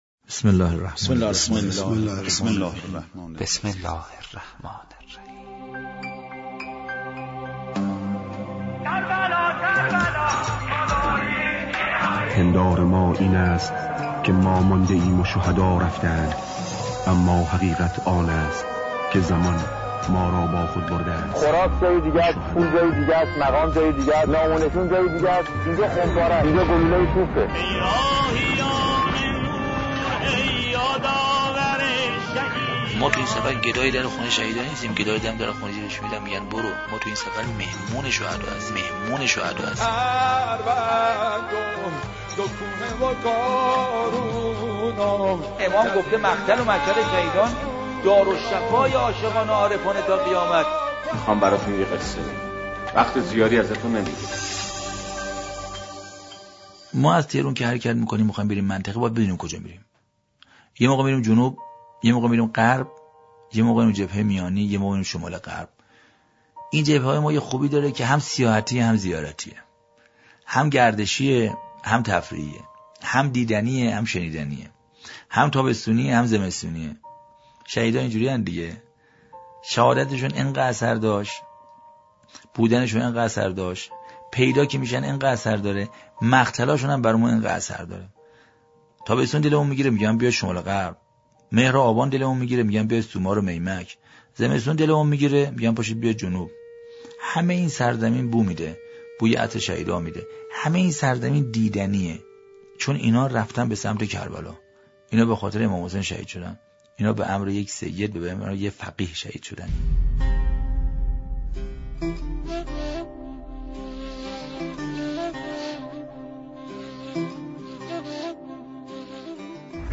مجموعه روایتگری از بهشتی به نام دوکوهه | به روایتگری حاج حسین یکتا و دیگر راویان دفاع مقدس